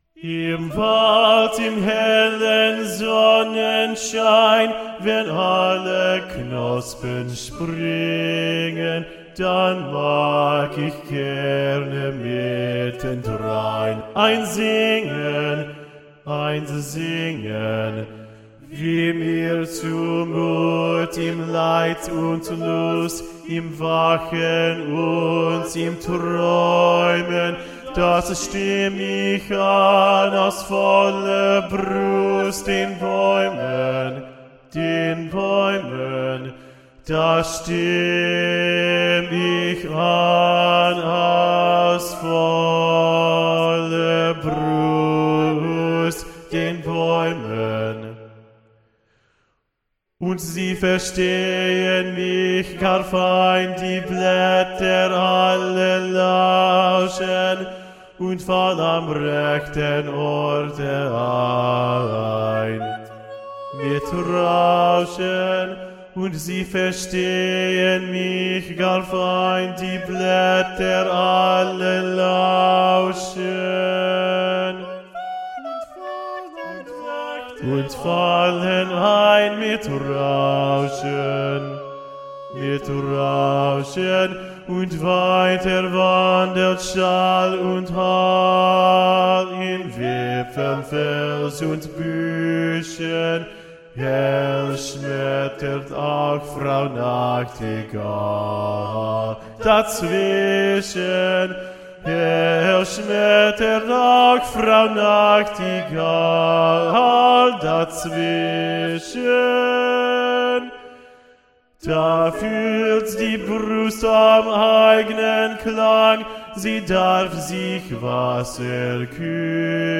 Basse(mp3)